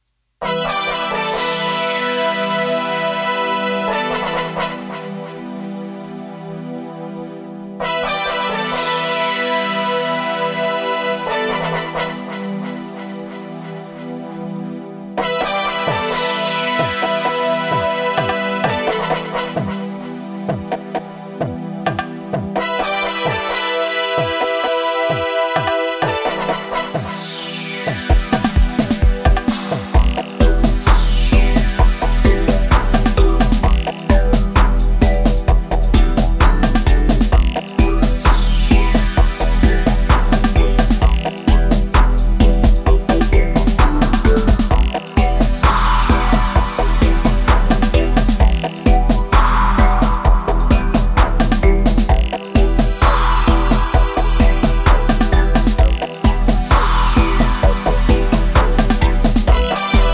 instrumentals
produced and mixed solely on analogue gear.
Both tracks are full of heavy and deep vibrations!